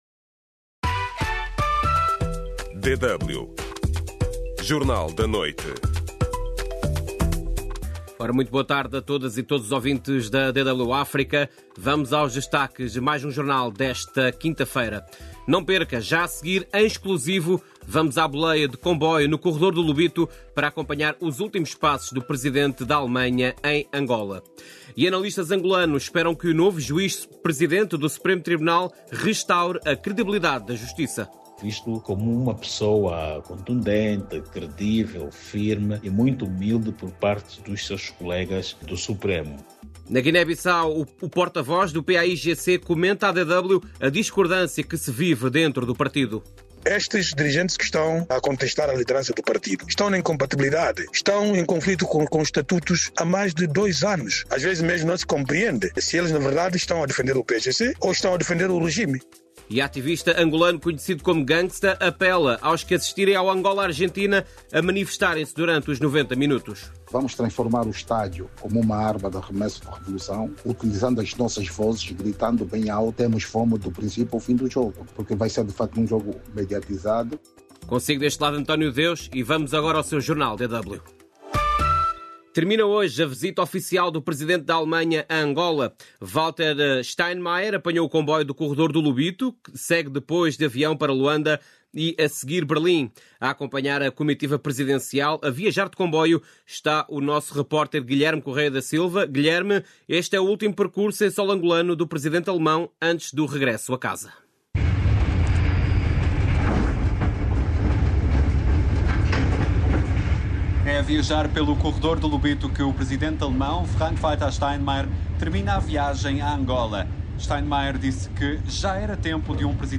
Notícias e informação de fundo sobre África, com destaque para Moçambique, Angola, Guiné-Bissau, Cabo Verde e São Tomé e Príncipe.